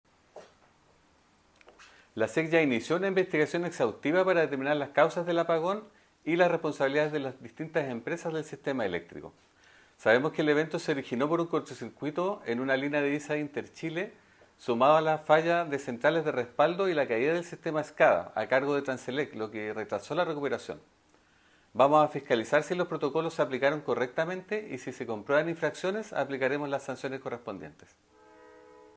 El director regional de la Superintendencia de Electricidad y Combustibles (SEC), Manuel Cartagena, explicó los pasos a seguir para investigar las causas del apagón y las responsabilidades involucradas.